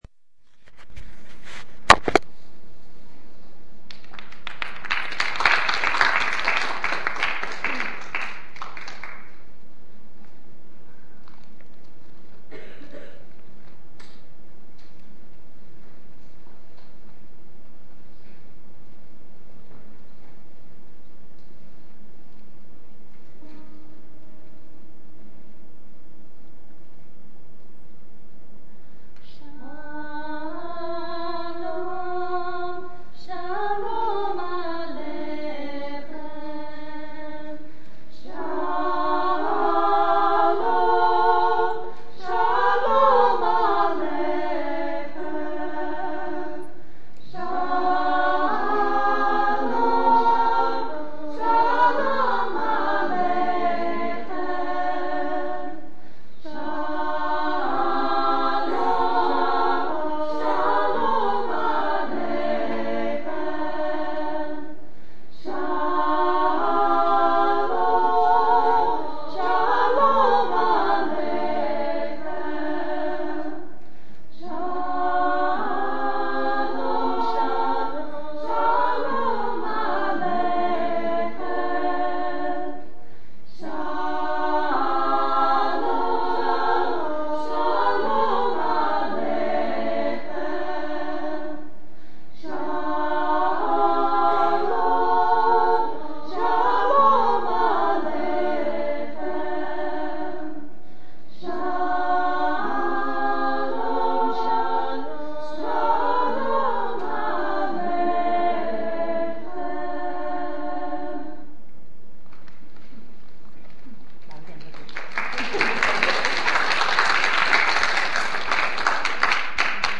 The Kol Isha Women’s Choir
recently performed in Passiac, New Jersey. The concert was hosted by the N’shei of the Clifton Cheder and Bais Yaakov.